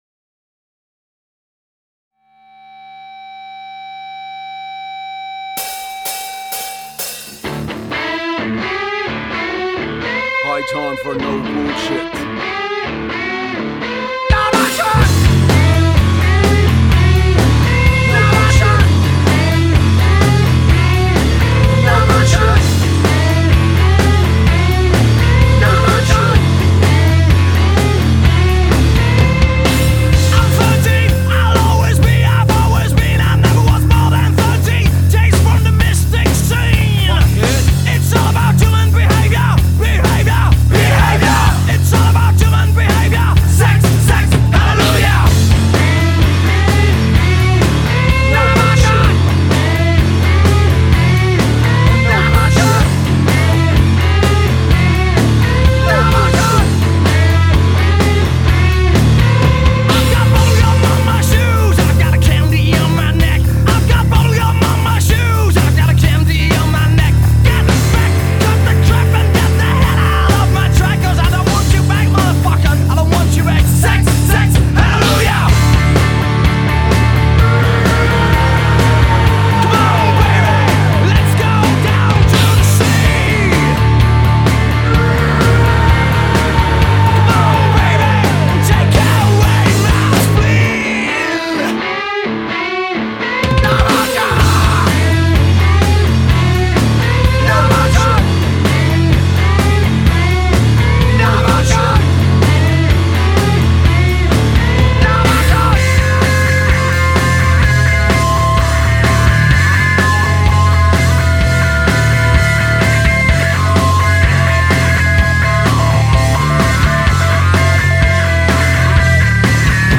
NASTY ROCK´N´ROLL